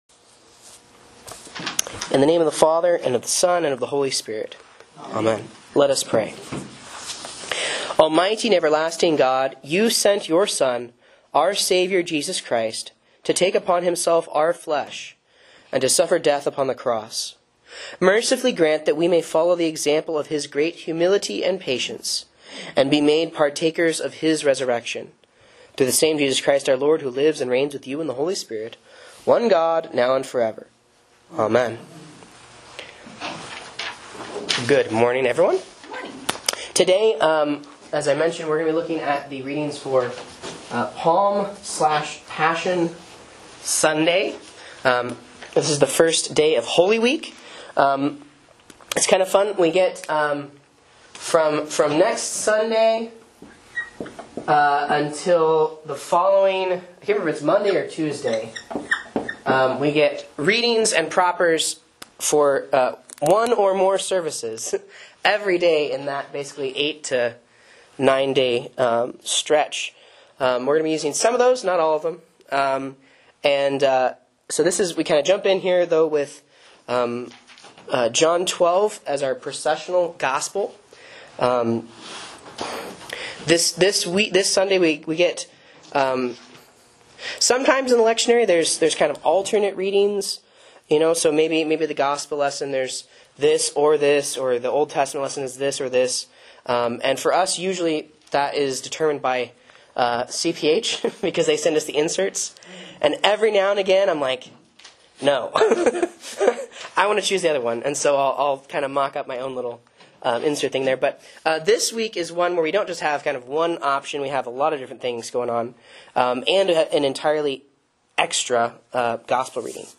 A Bible Class on the Lectionary Readings for Palm/Passion Sunday (C)